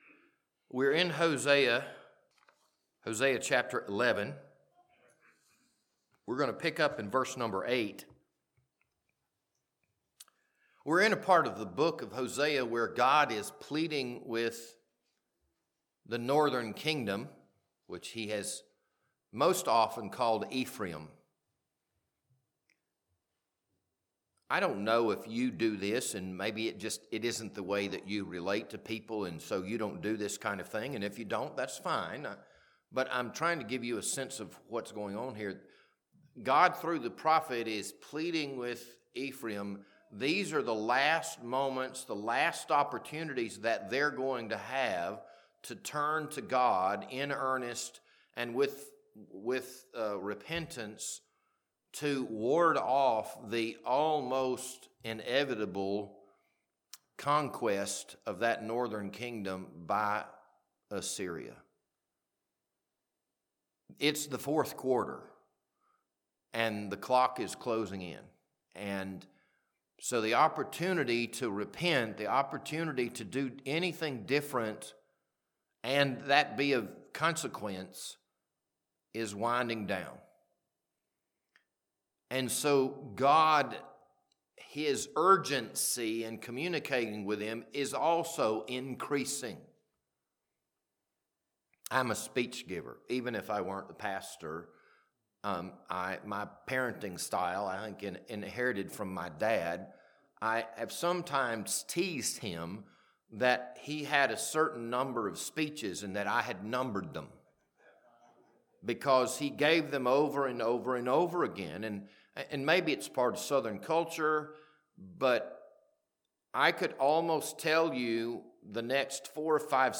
This Sunday evening sermon was recorded on January 18th, 2026.